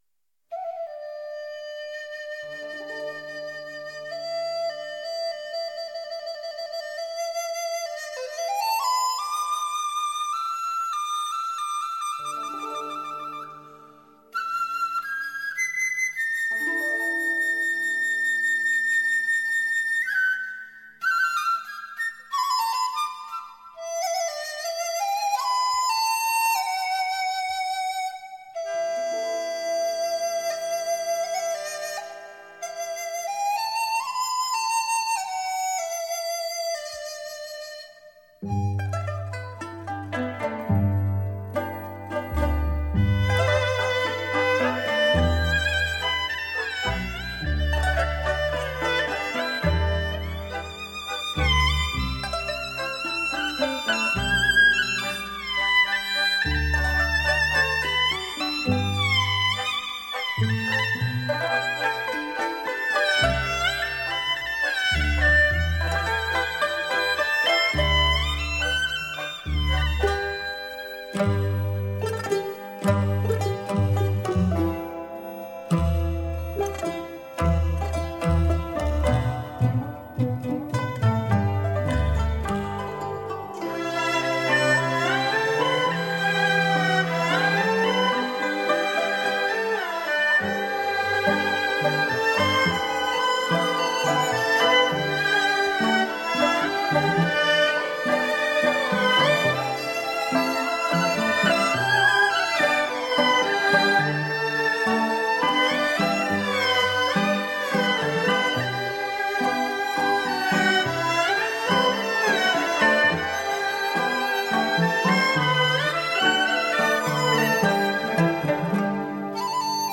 合奏) / 江南丝竹